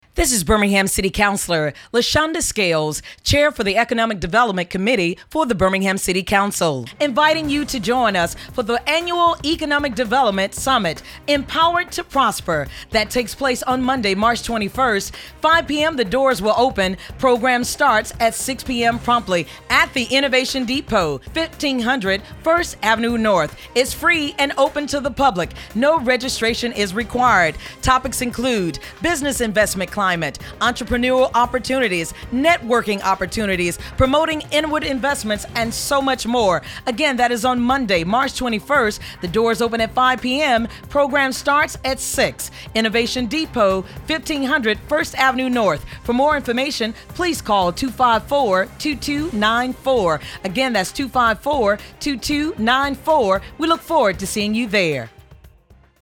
2011_Birmingham_Economic_Summit_spot.mp3